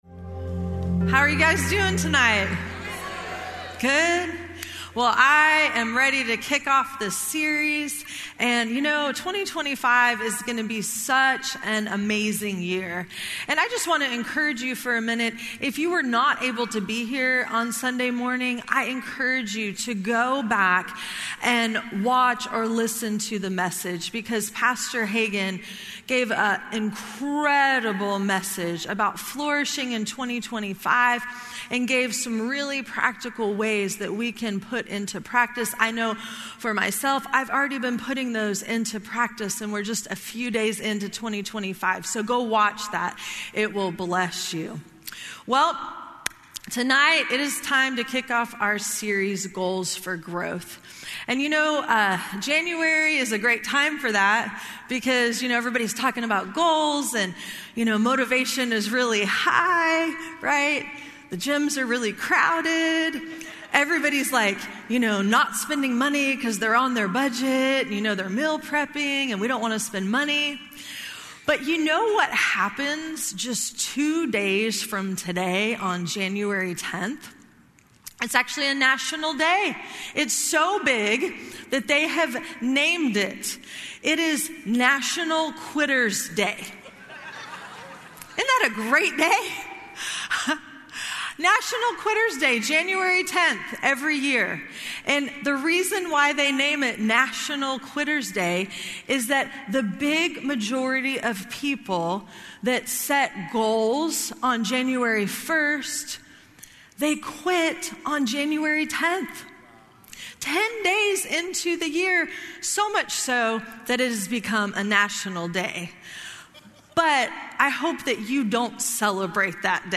Podcasts for RHEMA Bible Church services held at the Broken Arrow, OK campus.